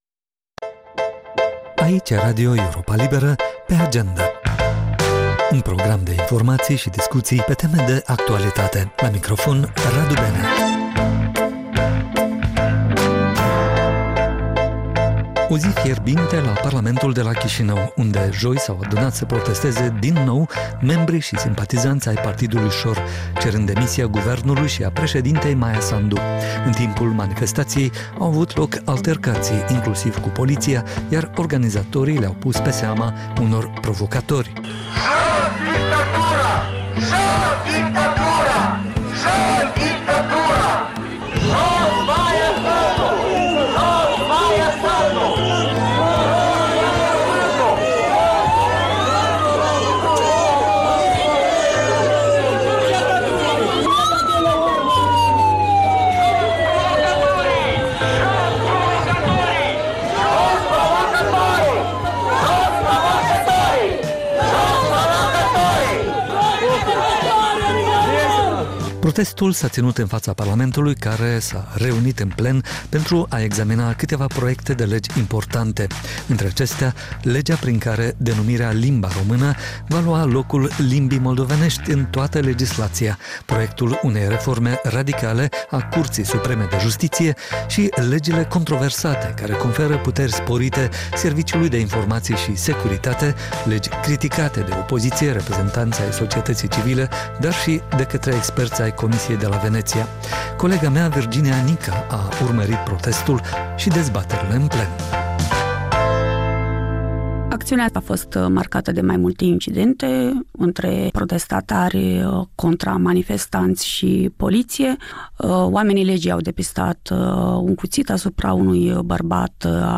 Programul de seară al Europei Libere. Ştiri, interviuri, analize şi comentarii.